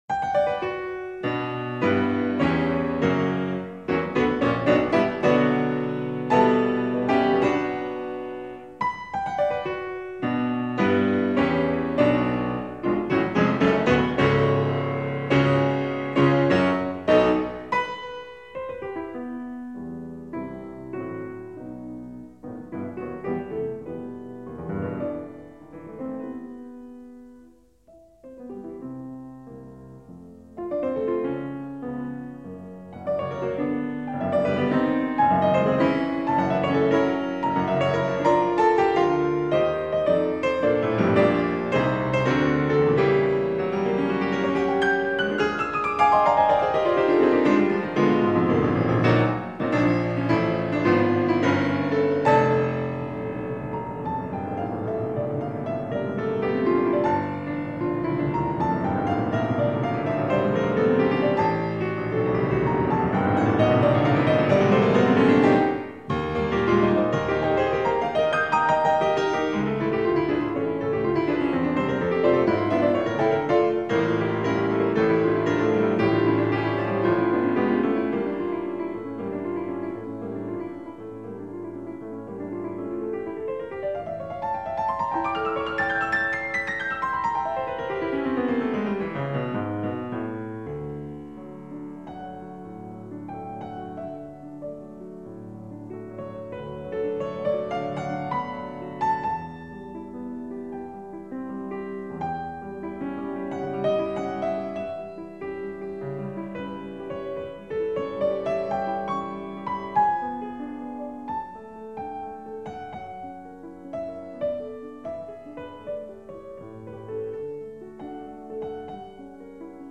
Piano sonata
A sonata written for a solo piano.